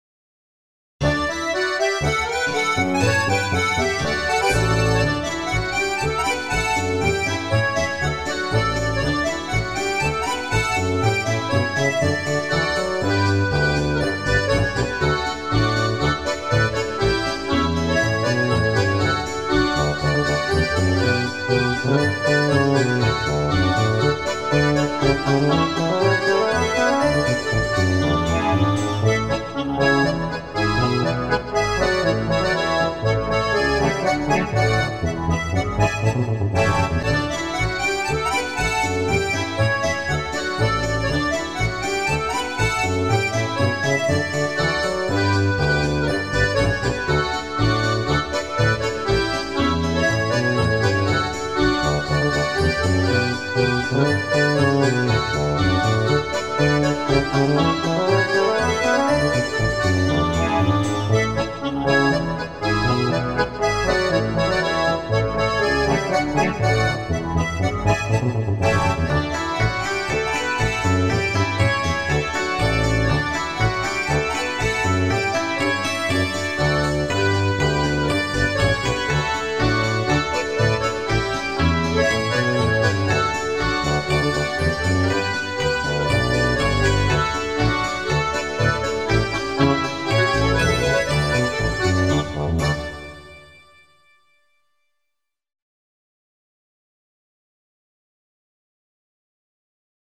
hola-hi-podklad.mp3